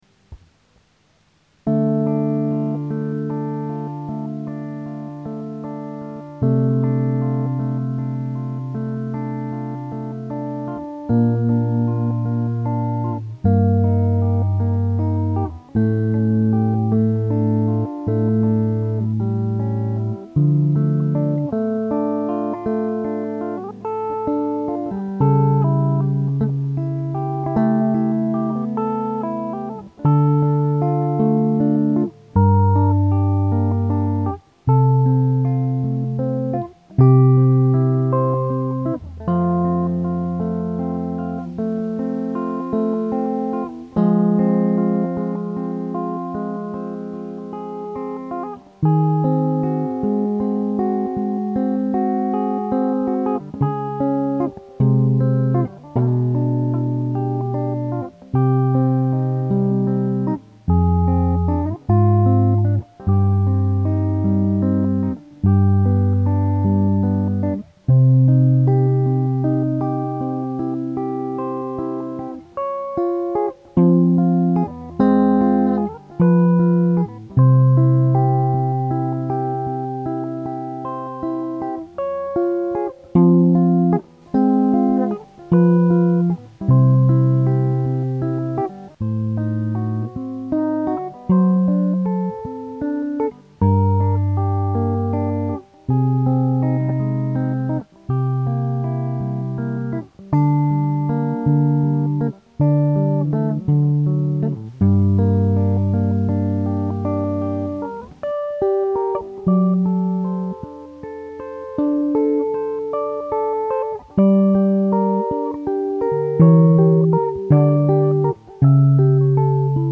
J'en profite donc pour partager de vieux enregistrements à la qualité...très imparfaite tant au niveau du son que musicale :newblush: .
LV Beethoven - Sonate No 14 "Clair de Lune" 1er mouvement Do# mineur, op27;2
La sonate au clair de lune à la gratte :excl: :shock: :') c'est trop beau